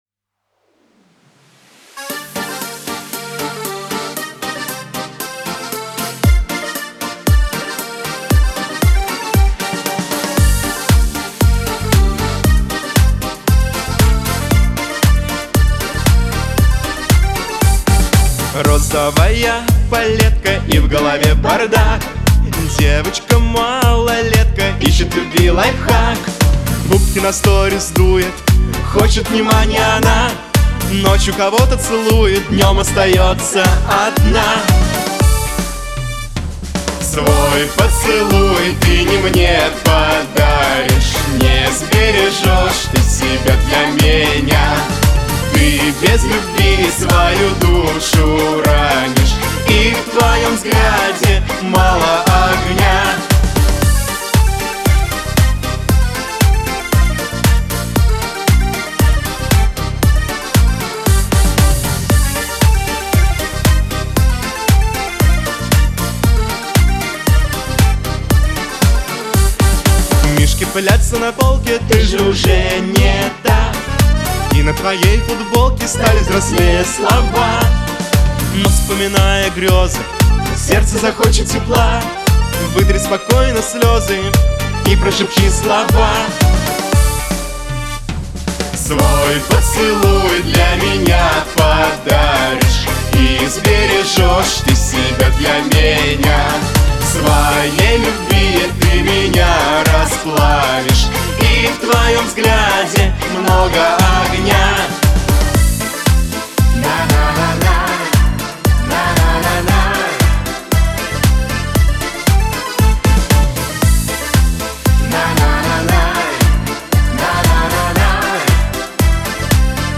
Лирика , dance